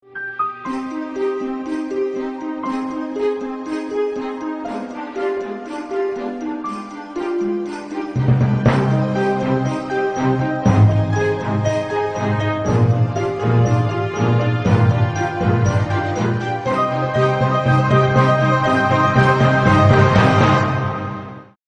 Kategorien Filmmusik